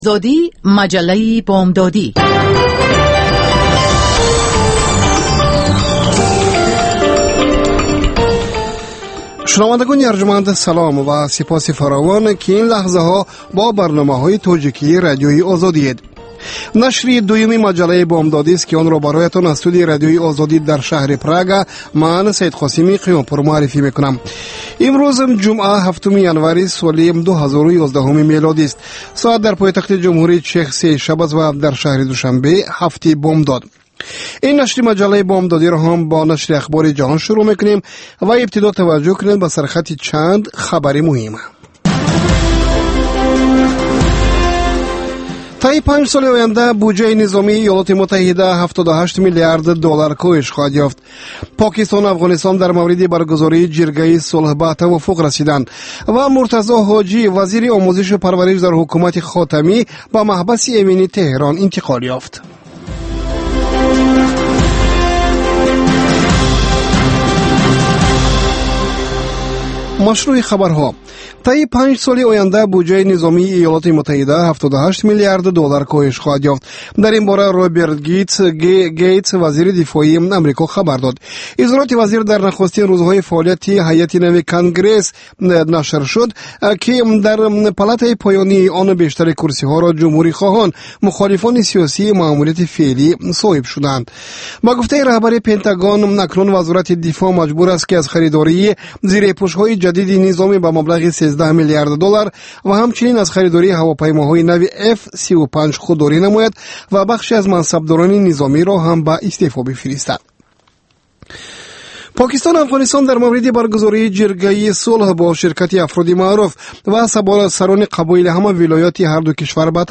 Охирин рӯйдодҳои ҷаҳон ва Тоҷикистон, хабар ва назар, таҳлил, мусоҳиба, гузоришҳо.